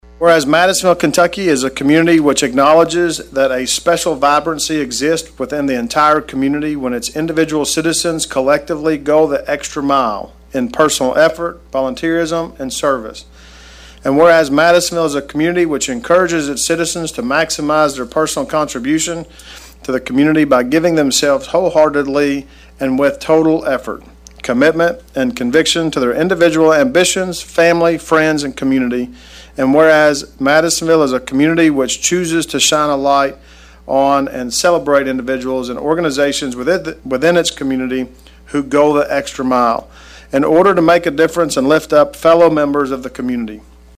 In a special session of the Madisonville City Council on Monday, Mayor Kevin Cotton officially declared November 1 as “Extra Mile Day,” urging residents to embrace the spirit of going above and beyond in their lives.
Mayor Cotton presented the proclamation to the Council.